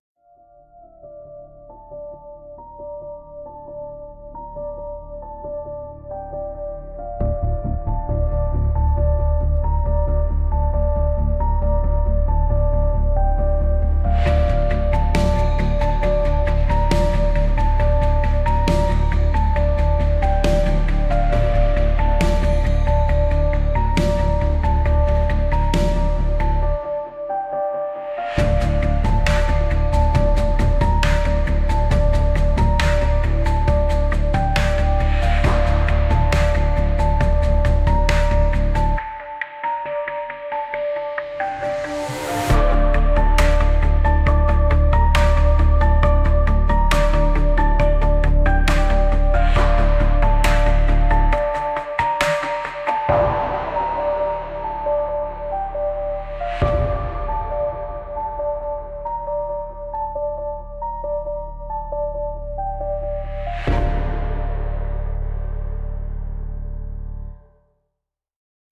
Lost hope - intense 1.08.mp3